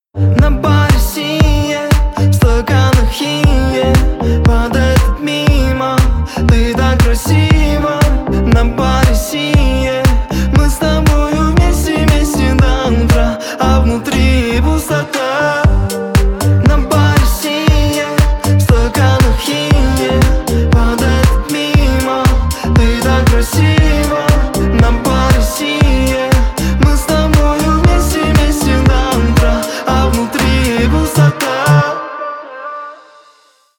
Поп Музыка
клубные # грустные